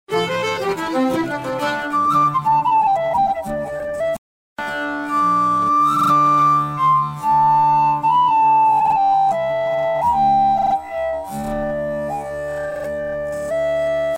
and the whistle player sounds marvellous.
To my wooden ears, it’s a tongued double cut.